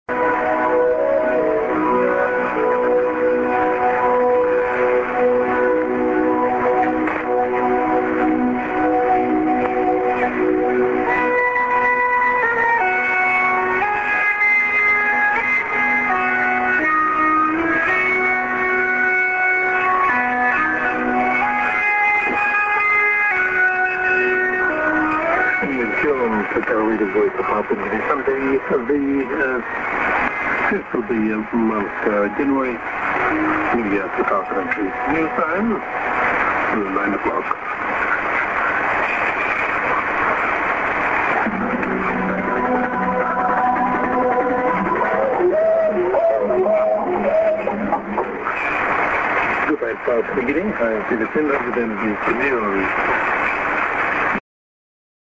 music->ANN(man)->SJ->ID:NBC news(man)　以前は、ＴＳの前にＩＤが出た記憶が、今回はありません。